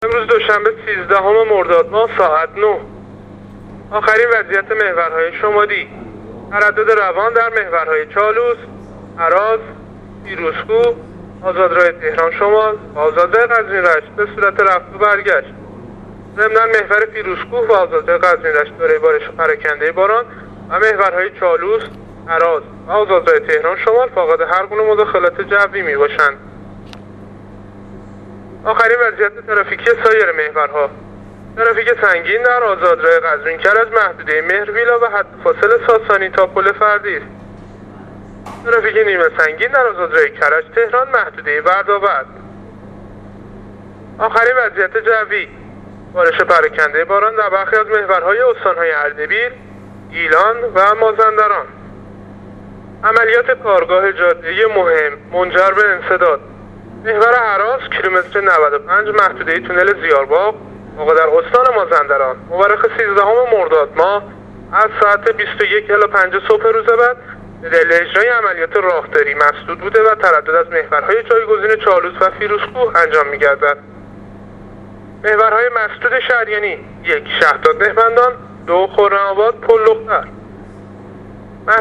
گزارش رادیواینترنتی از وضعیت ترافیکی جاده‌ها تا ساعت ۹ سیزدهم مرداد